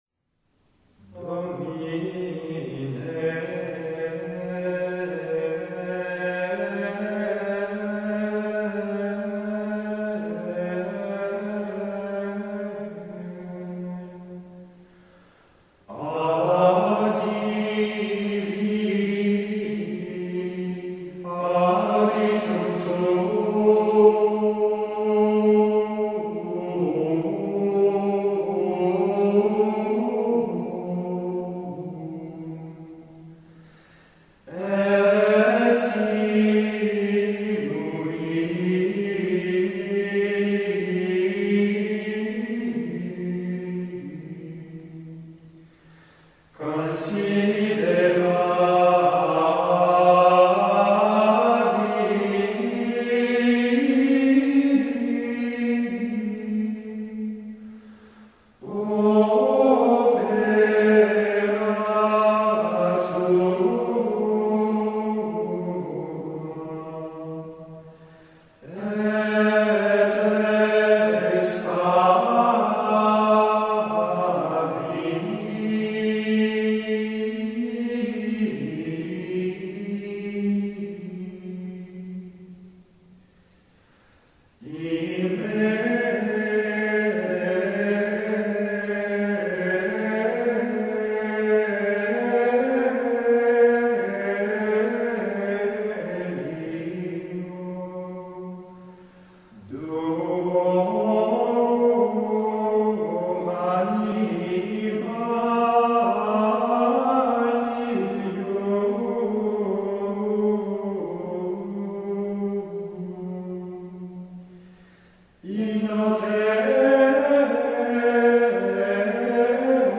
Célébration de la passion du Seigneur, trait après la 1re lecture, Début du cantique d’Habacuc selon les Septante (Habac. II, 2).